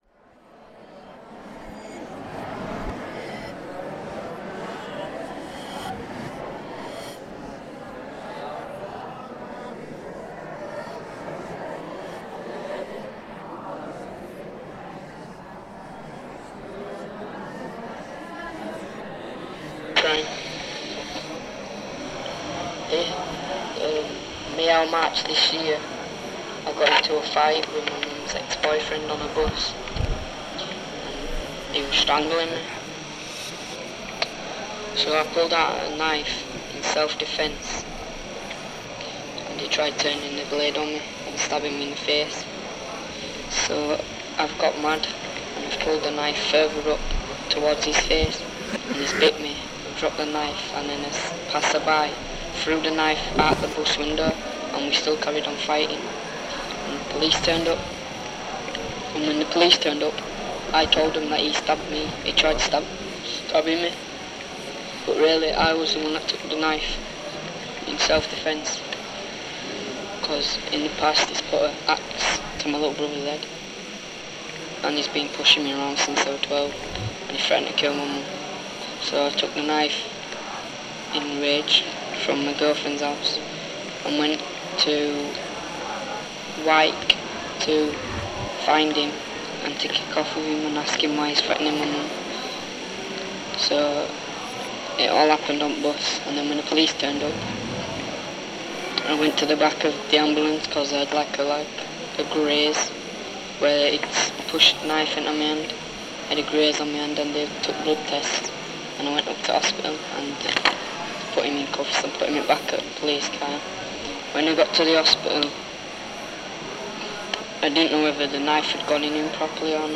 An extract from a sound installation made with a group of young people